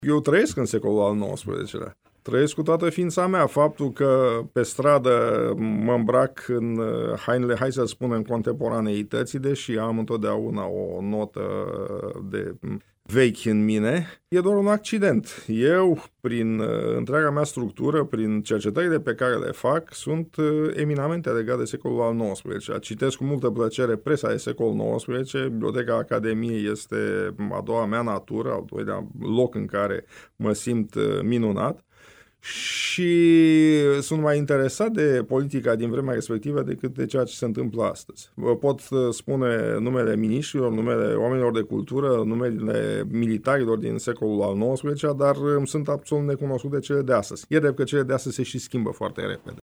Promo